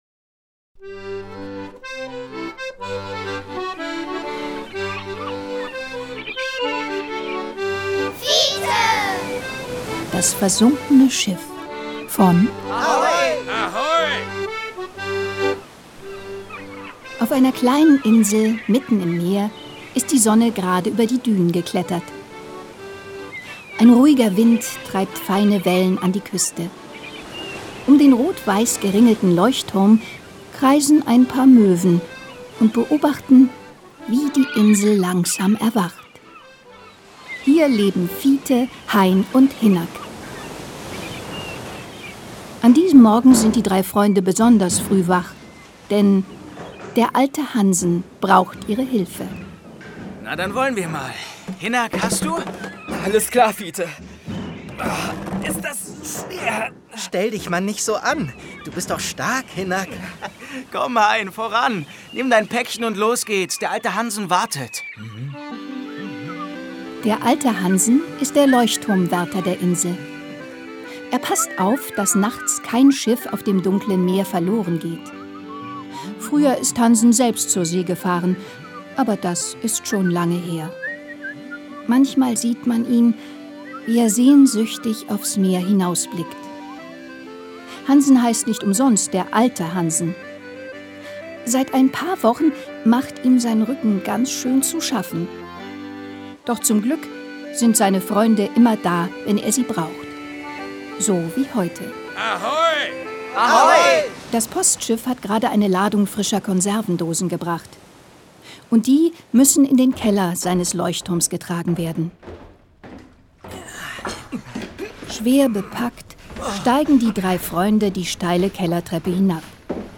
Hörbuch: Fiete - Die ersten drei Abenteuer